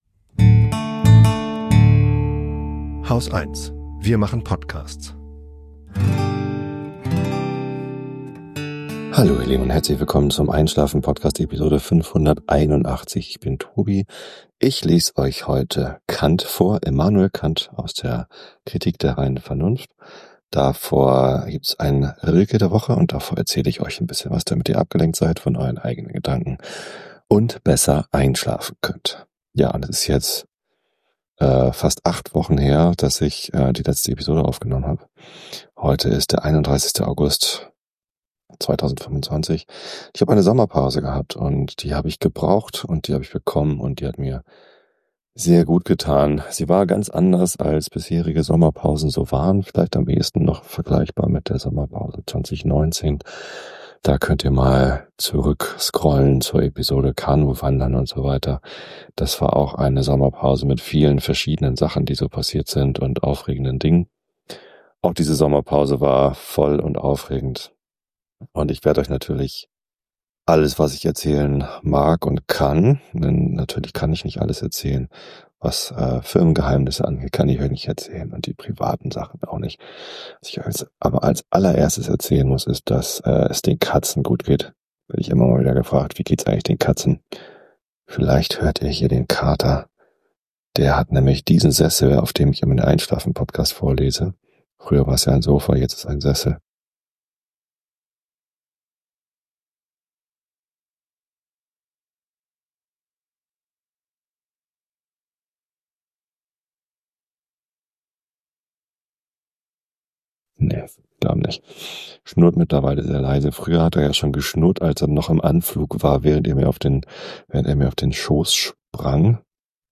Monologe zum Entspannen